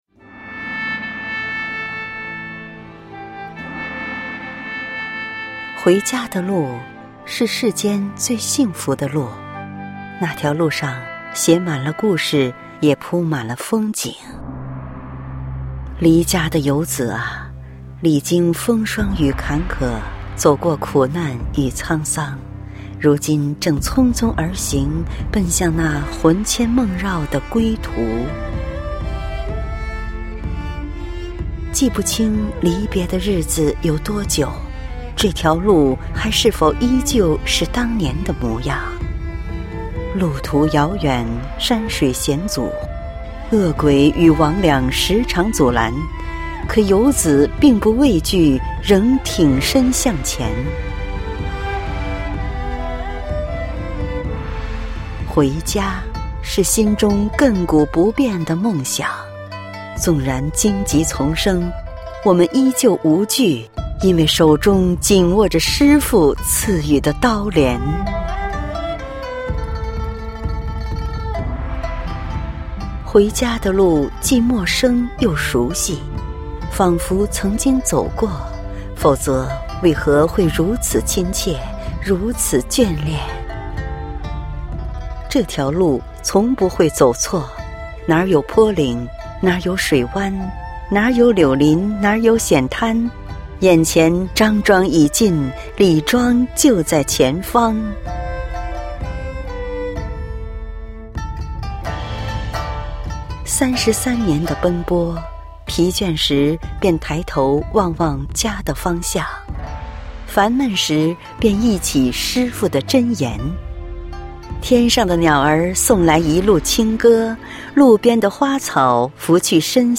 配樂散文朗誦（音頻）：回家的路